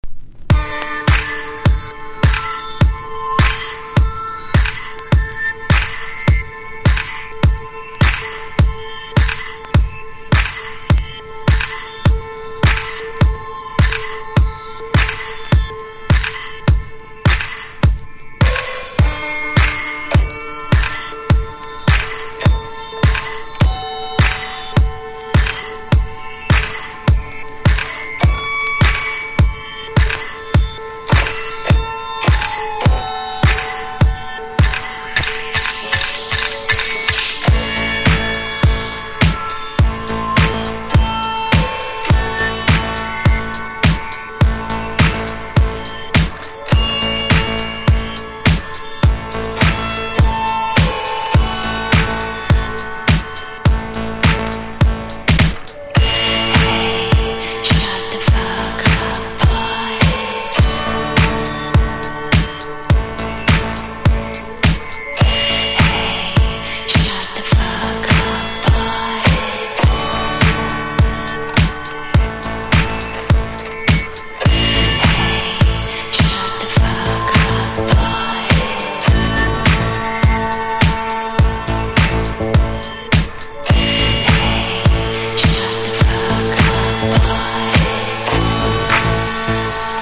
1. 00S ROCK >
NEO ACOUSTIC / GUITAR POP